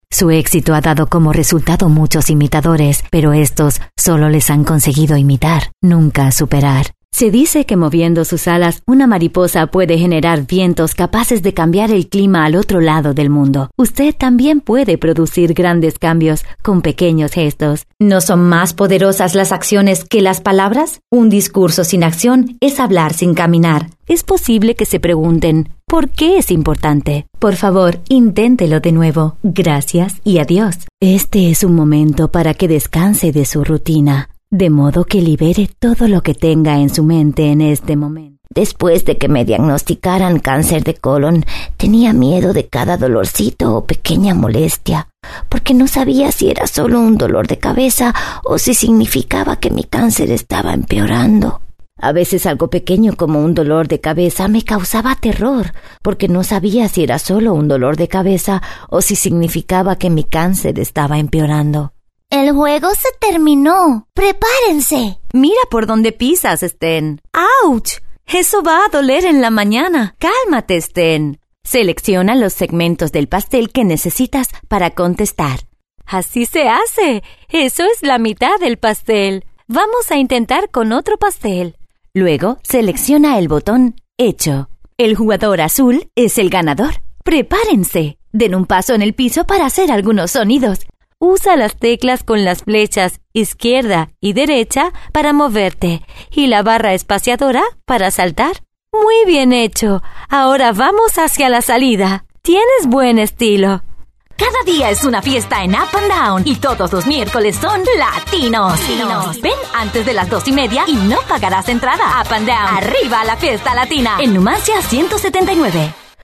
Actriz de doblaje y cantante con registro infantil, adolescente, adulto e incluso abuelitas.
Sprechprobe: Industrie (Muttersprache):
My voice is warm and clear for narrations, fun and young for commercials, professional and smooth for presentations.